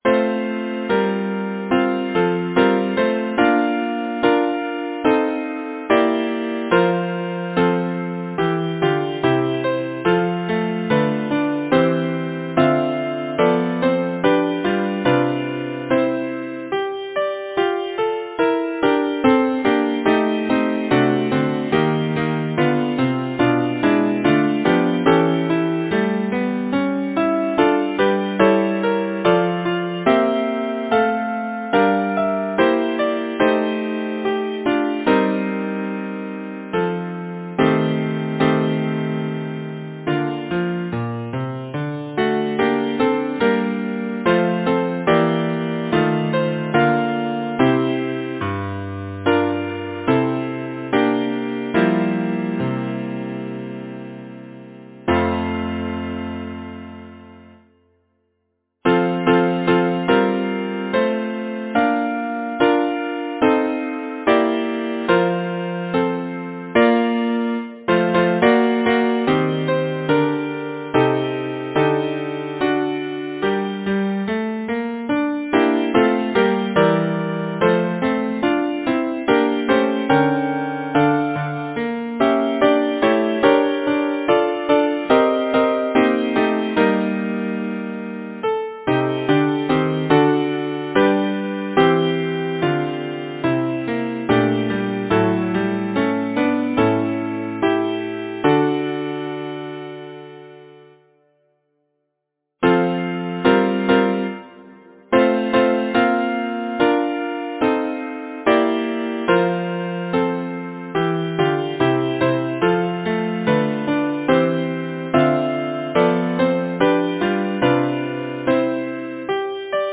Title: Chloris! yourself you so excel Composer: Fritz Bennicke Hart Lyricist: Edmund Waller Number of voices: 4vv Voicing: SATB Genre: Secular, Partsong
Language: English Instruments: A cappella